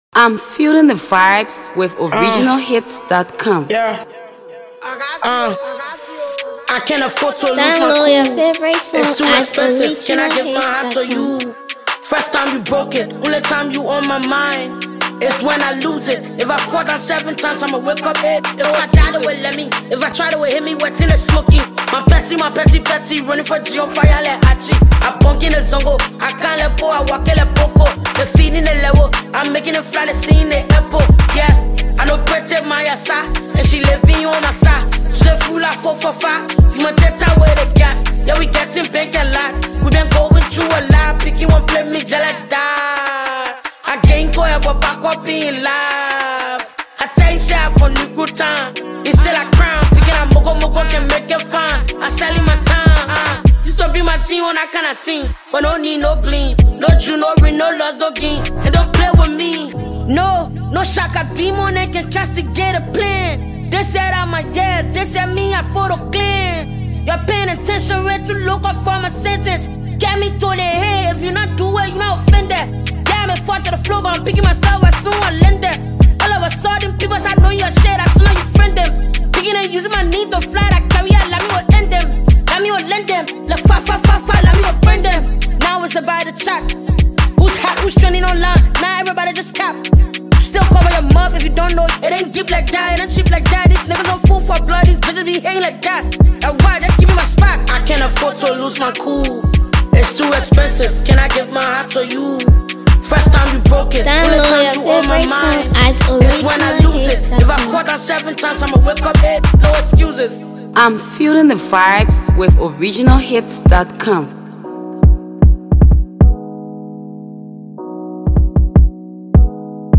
Liberia heavyweight female rapper
hot rap jam